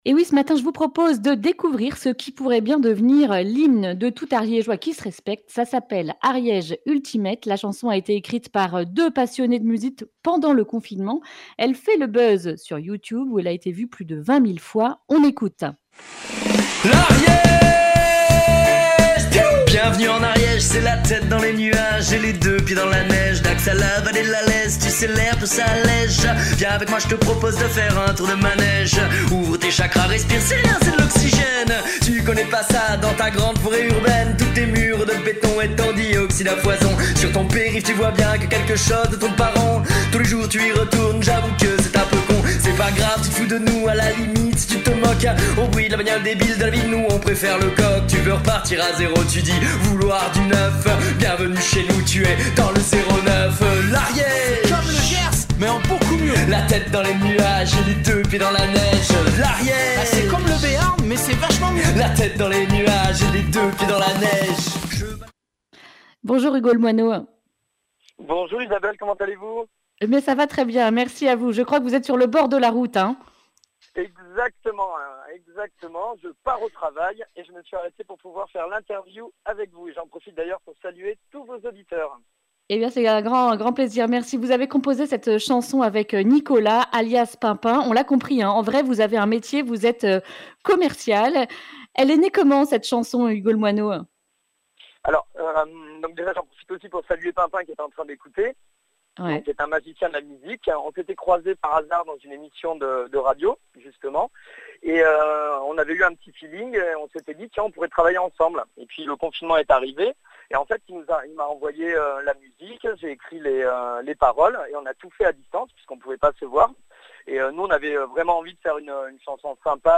mardi 16 juin 2020 Le grand entretien Durée 10 min